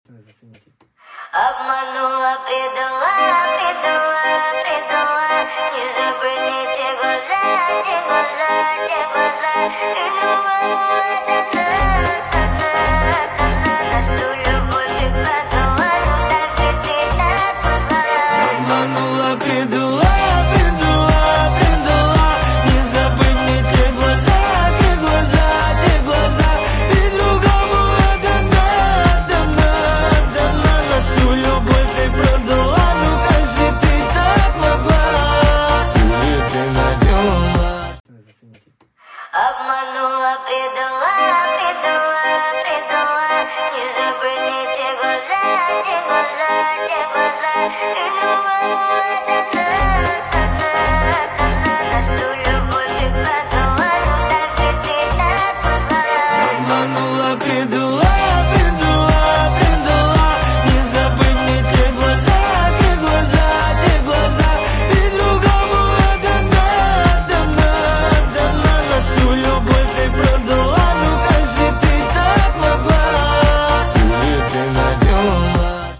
Русские поп песни, Грустная музыка